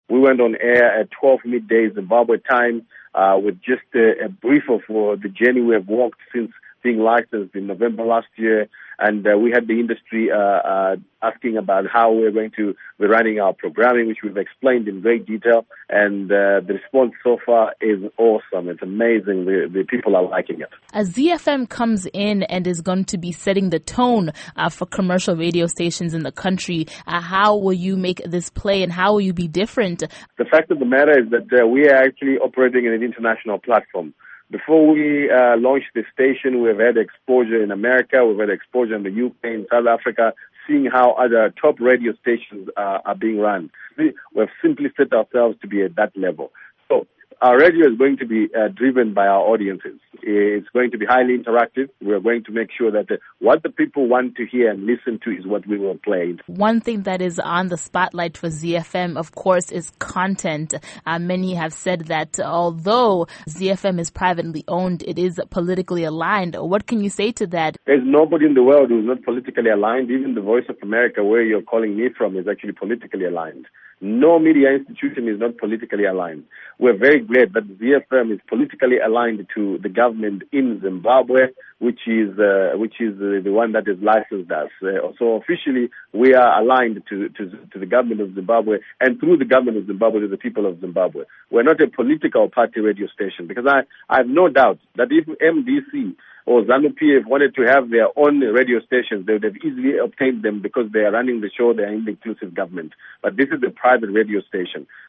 Interview With Supa Mandiwanzira